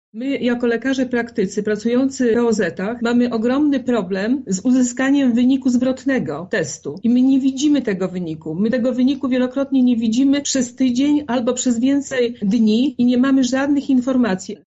Problem na XX sesji Sejmiku Województwa Lubelskiego przybliżyła radna Zofia Woźnica